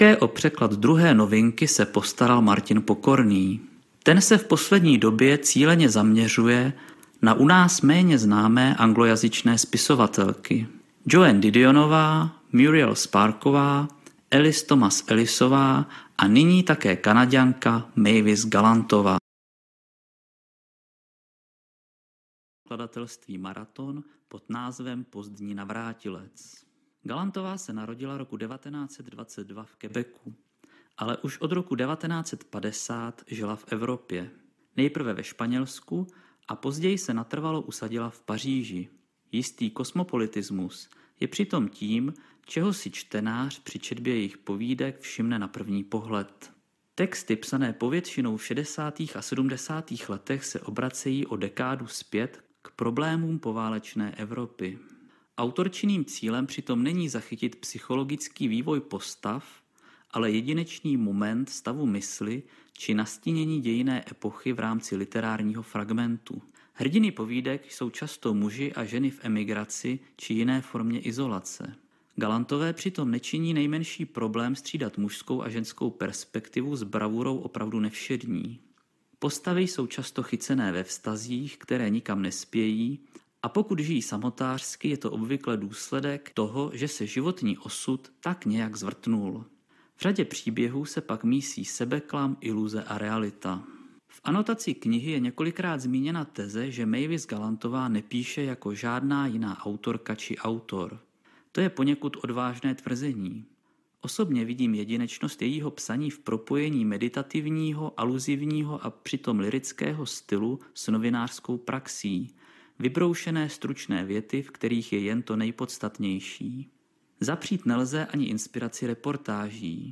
RECENZE